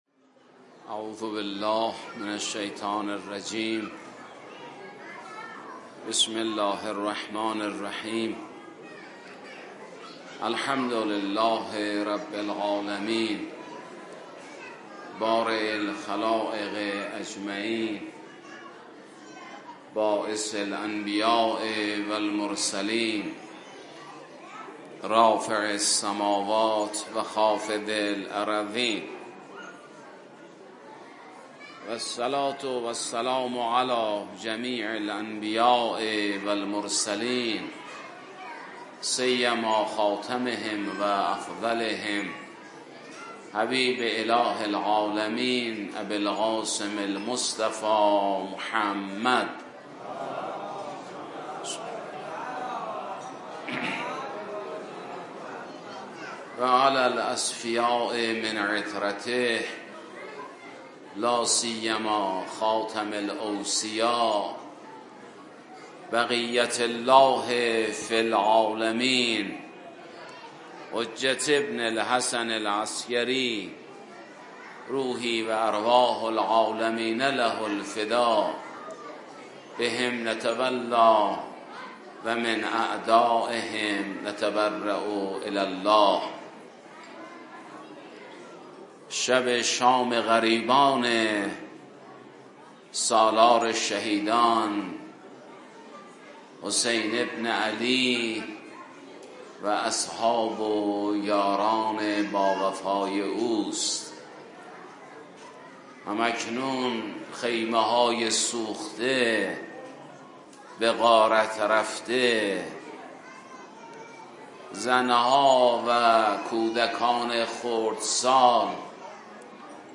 در شب شام غریبان حسینی